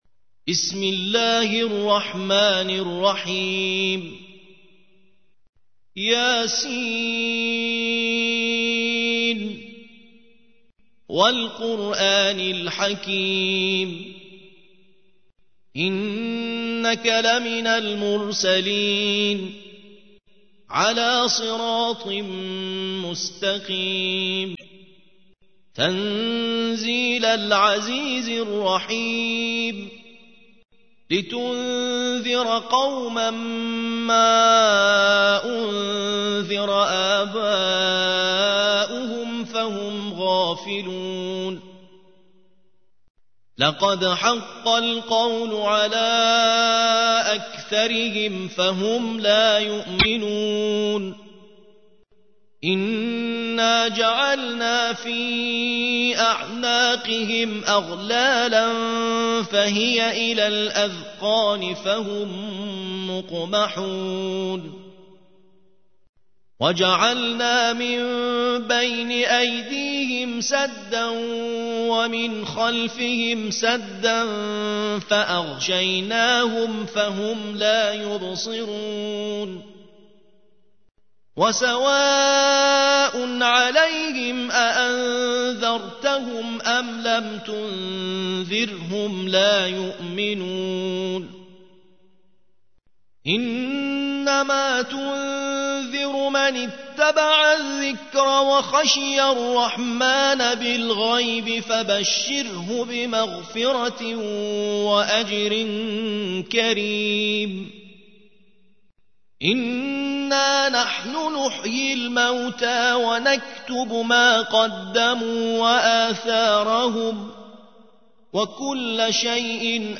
36. سورة يس / القارئ